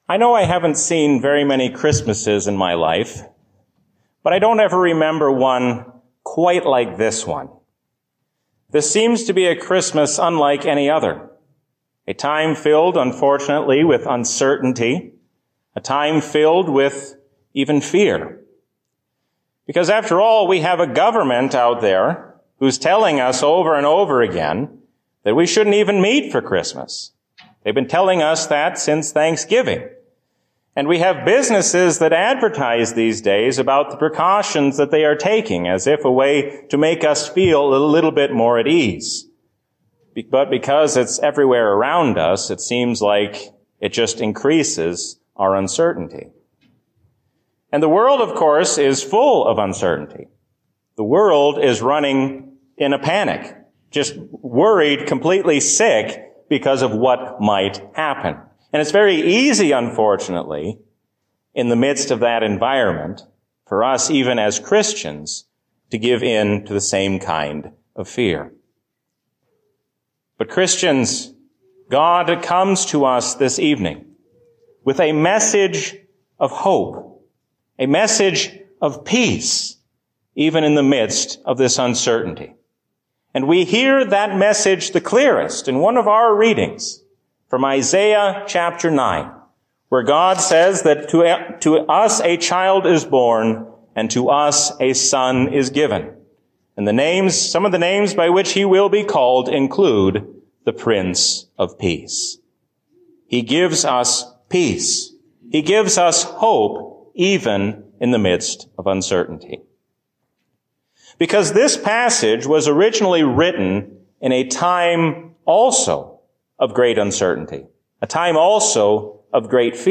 A sermon from the season "Trinity 2022." God is your loving Father and gives you the strength to face all of life's troubles.